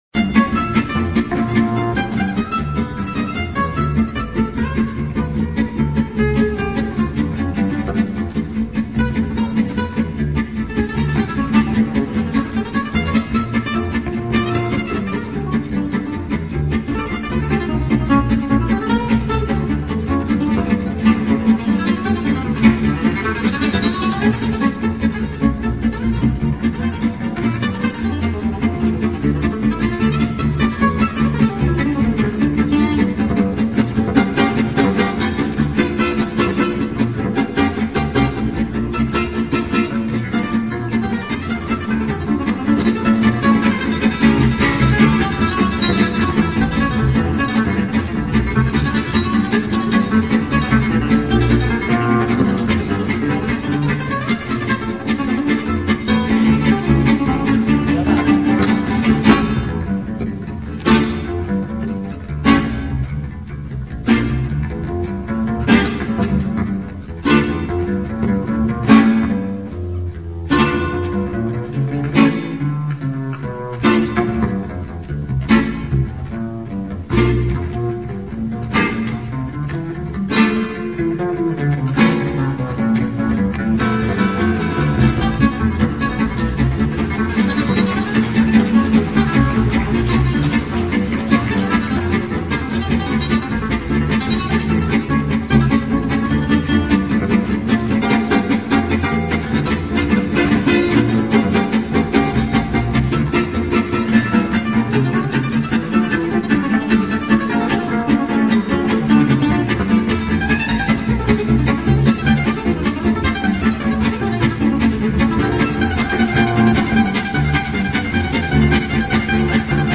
extrait concert "hommage à Django" du 27/06/03